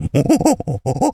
pgs/Assets/Audio/Animal_Impersonations/monkey_chatter_02.wav at master
monkey_chatter_02.wav